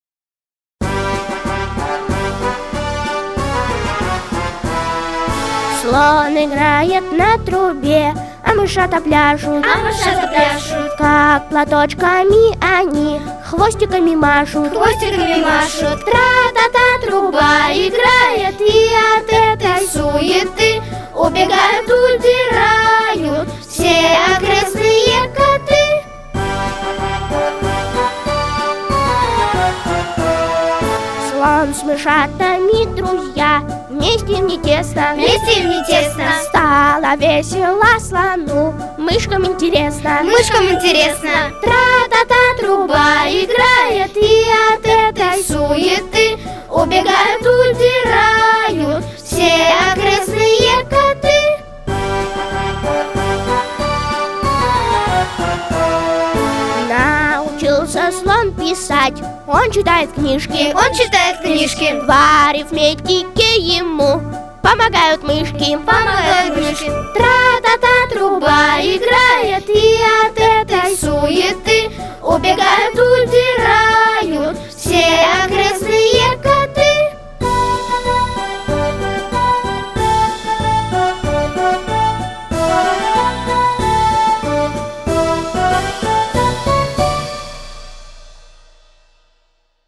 III Театральный Фестиваль начальной школы